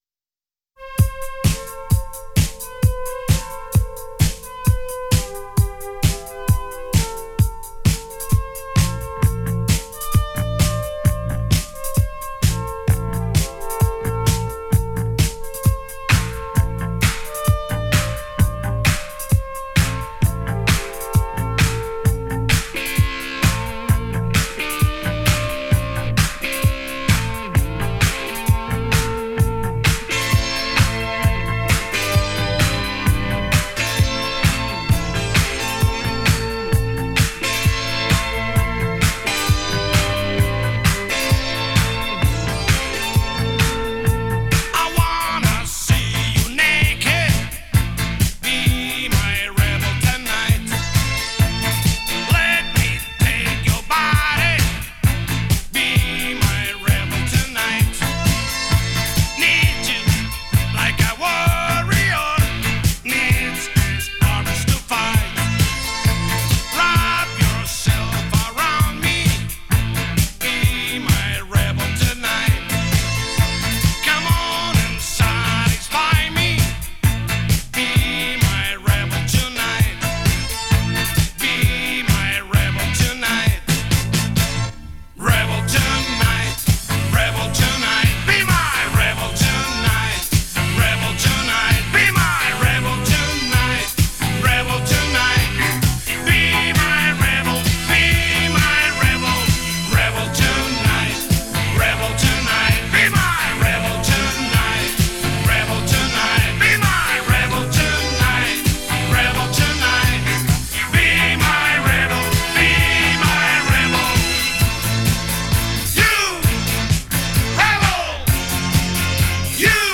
играющая в стиле R & B и Funk Rock